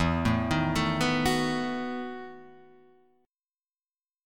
EM7sus2sus4 chord